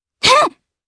Nia-Vox_Attack3_jp.wav